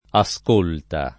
scolta [Sk1lta] s. f. — da un incrocio di scolca con ascoltare — es. con acc. scr.: posta come scólta alla vedetta d’una nuova Italia [p1Sta k1me Sk1lta alla ved%tta d una nU0va it#lLa] (Carducci) — antiq. ascolta [